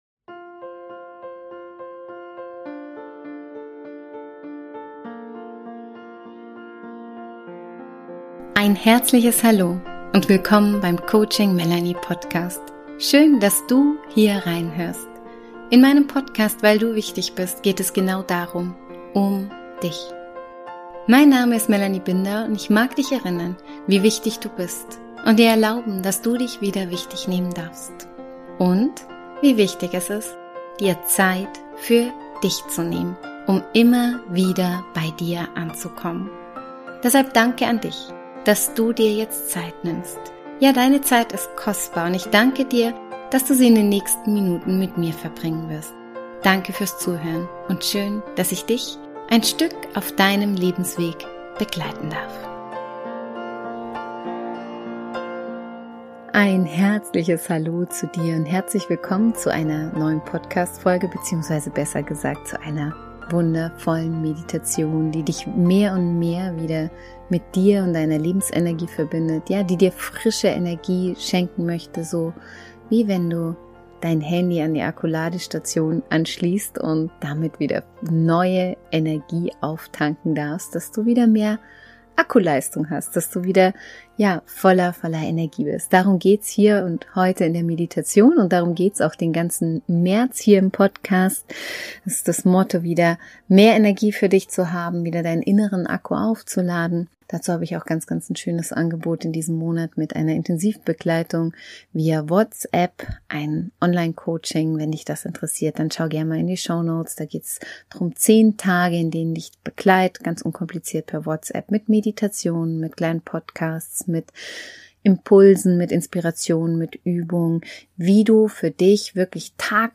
Nimm dir diese wertvollen Minuten nur für dich – und spüre, wie du deine Lebensenergie wieder mehr in Fluss bringst und neue Kraft tankst. Falls du direkt mit der Meditation (ohne Einführung) starten möchtest, dann spring gerne zu Minute 2:27, dort startet die Meditation.